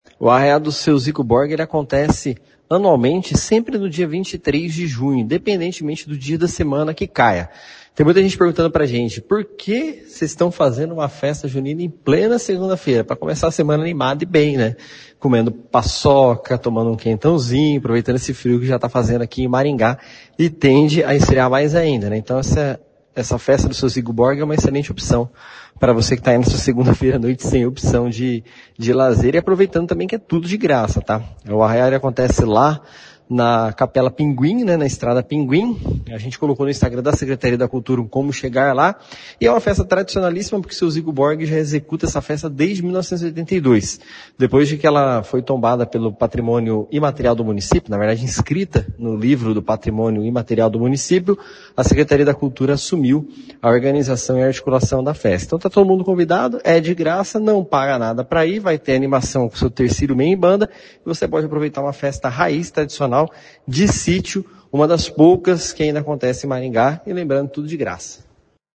O Arraiá do Seo Zico Borghi é tombado como Patrimônio Imaterial de Maringá e nos últimos anos está a cargo da prefeitura, por meio da Secretaria de Cultura. A festa é na Estrada Pinguim, ao lado da Capela Bom Jesus, com direito a quadrilha, bailão e quitutes juninos de graça, como detalha o Secretário de Cultura, Tiago Valenciano.